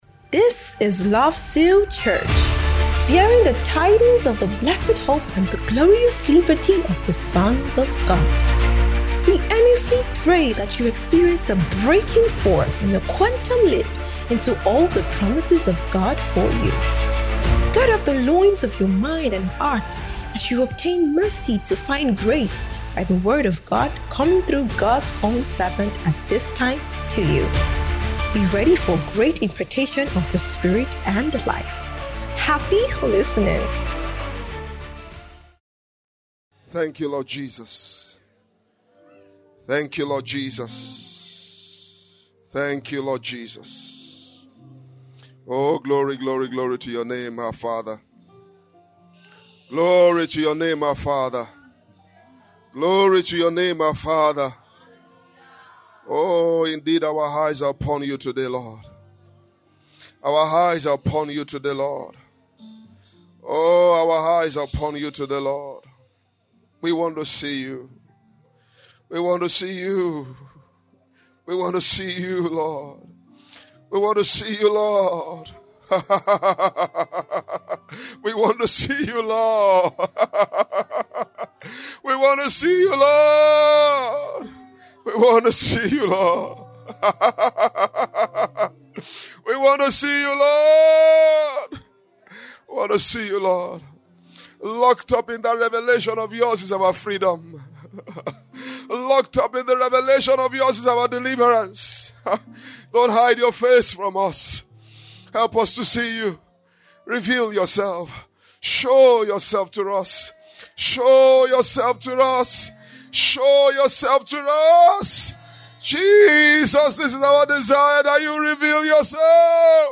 Transfiguration Bootcamp 2025 (D1AM)